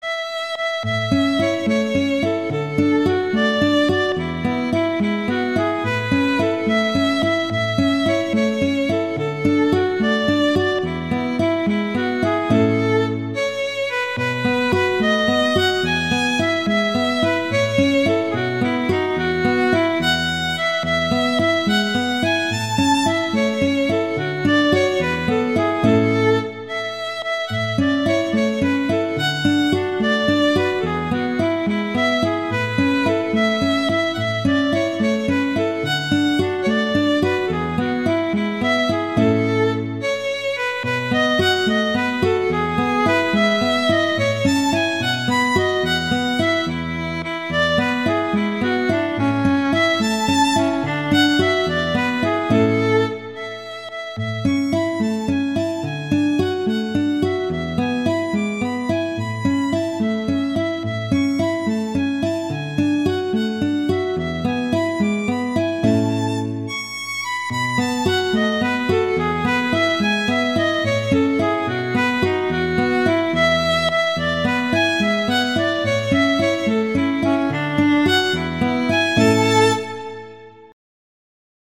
violin and guitar
classical